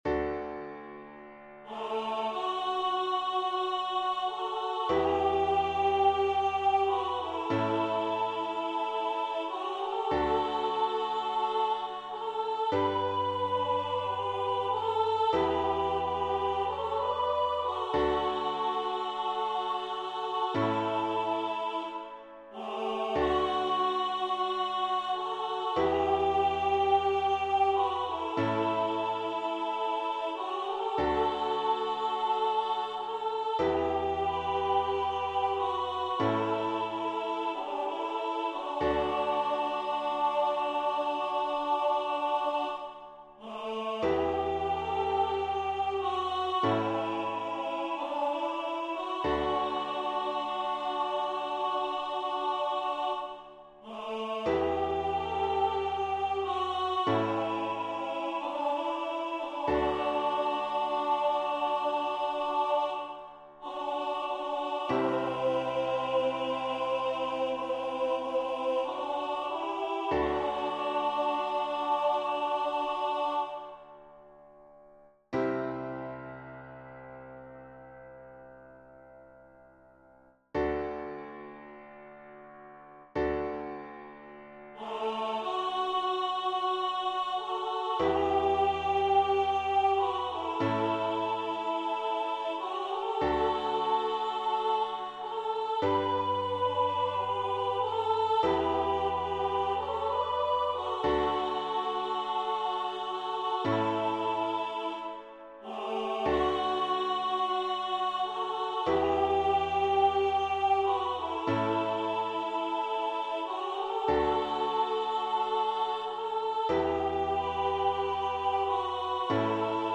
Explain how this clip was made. Rehearsal file